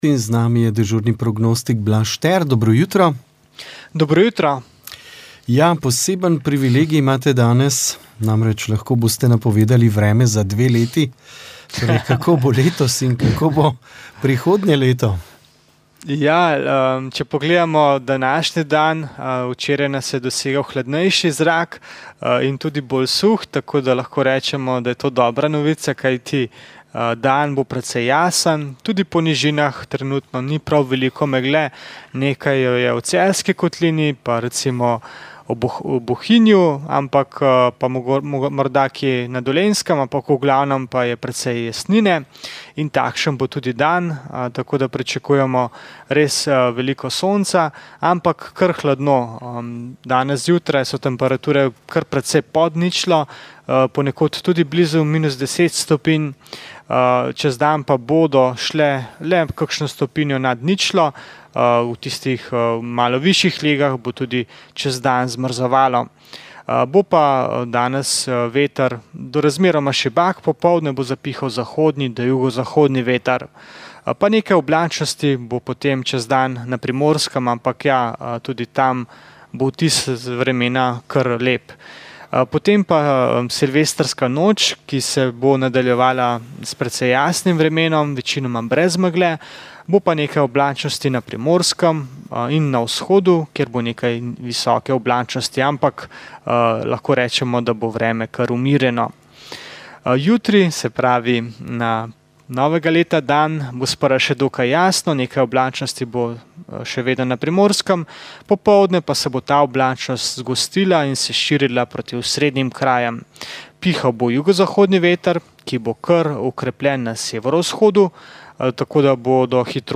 Sveta maša
Sv. maša iz stolne cerkve sv. Janeza Krstnika v Mariboru 22. 9.